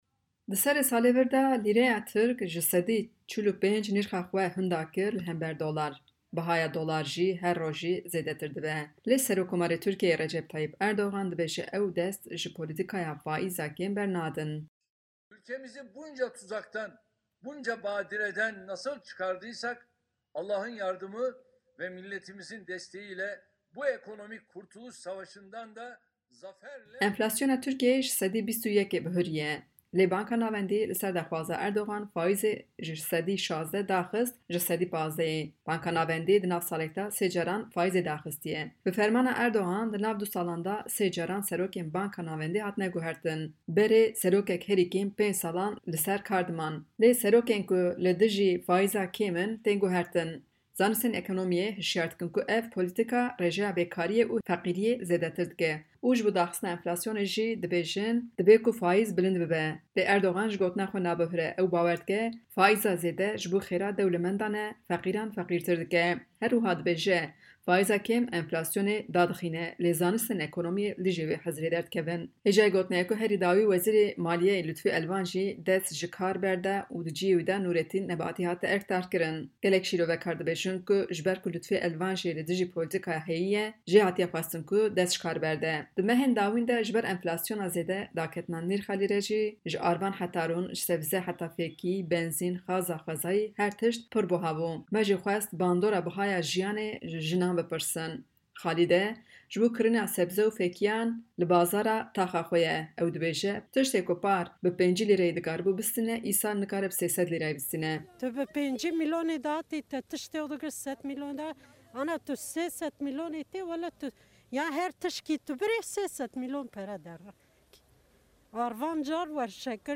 Dengê Amerîka li bazaran ji runiştvana pirs kir ka ev rewş li ser bandorek çawa çêkirîye.